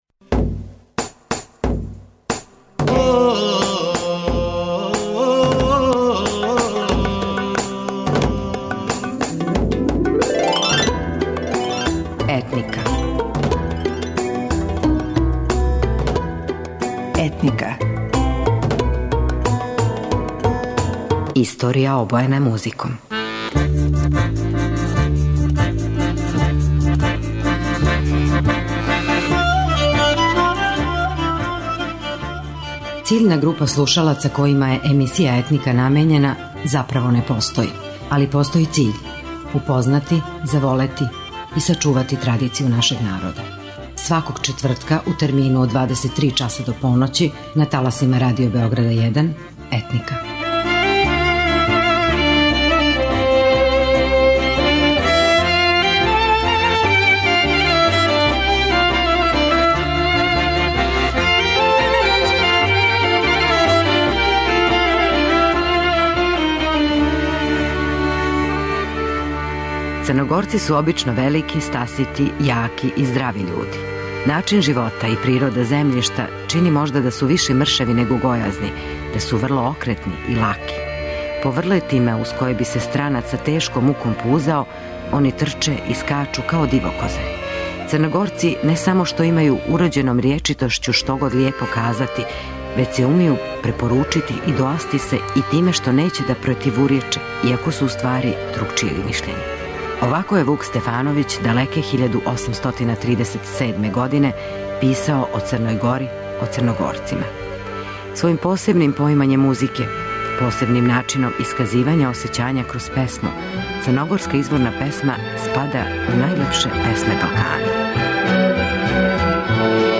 Мало је толико осећајних, емотивних песама као што су црногорске.
Допадљиве и мелодичне, дуго живе у народу не препуштајући се забораву.